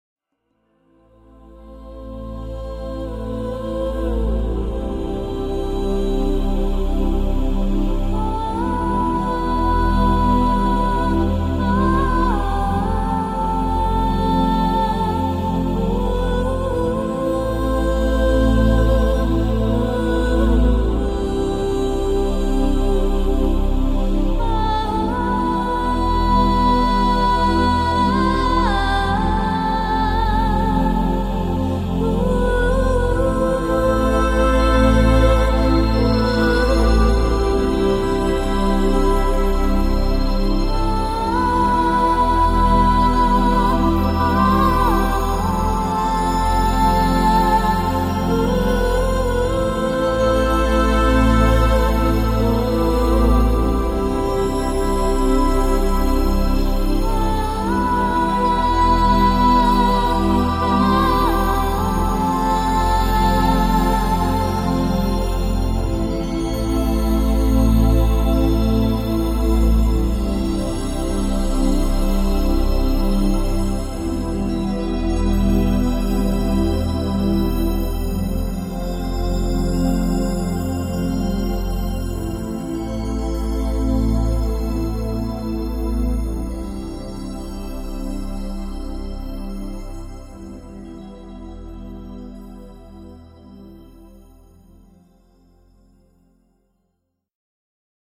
熟悉的旋律重温回忆中的浪漫，悠扬的音符盘旋在寂静的空中。
最出色的十三首作品，首首旋律优美，流畅的吉他、深情的钢琴、感